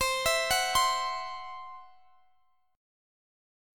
Listen to CMb5 strummed